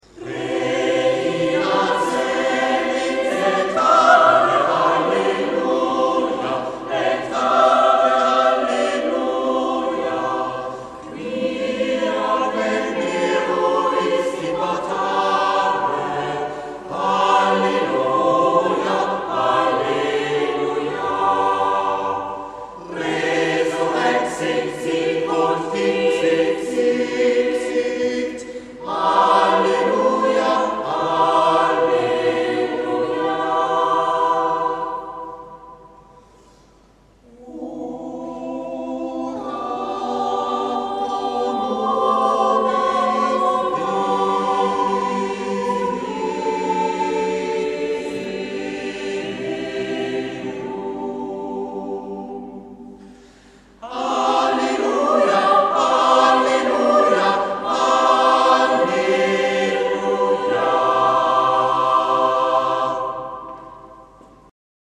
Auferstehungsmesse Ostern 2011